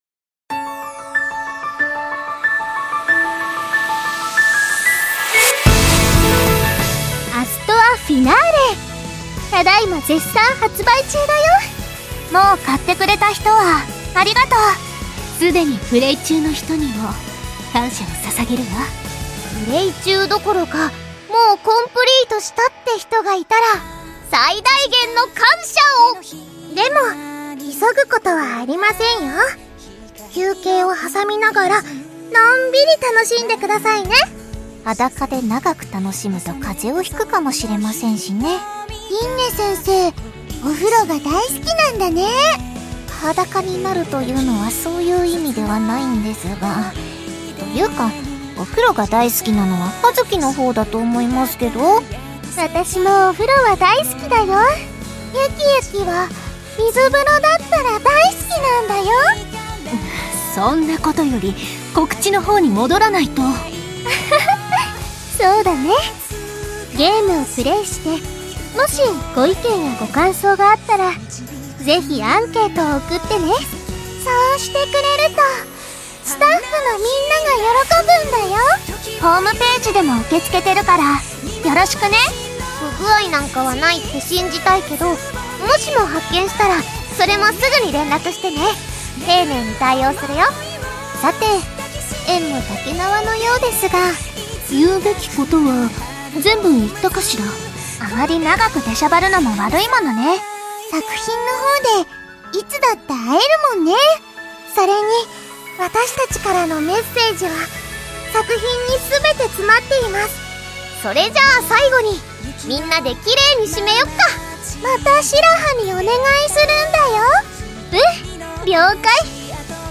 『アストラエアの白き永遠 Finale』 発売記念ボイス2を公開